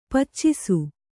♪ paccisu